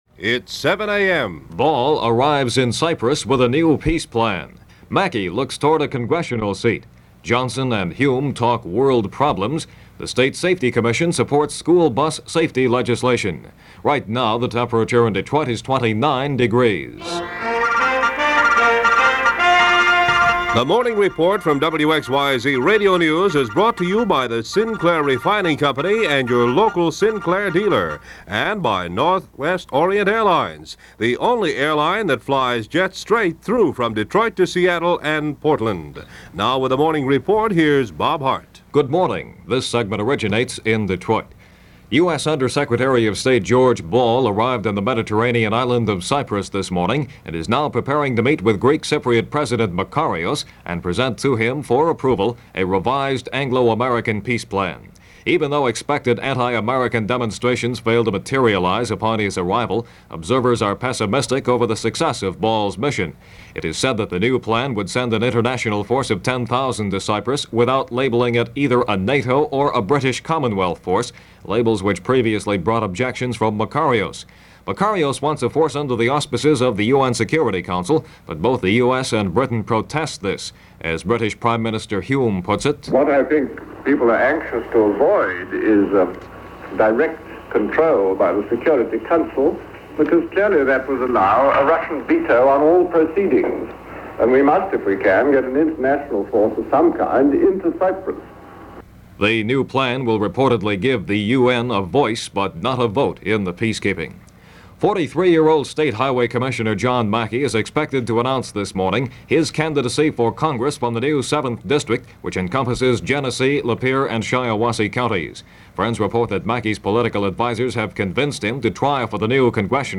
February 12, 1964 - Big Trouble In Little Cyprus - news of fighting escaltating between Greek and Turkish Cypriots on the island of Cyprus.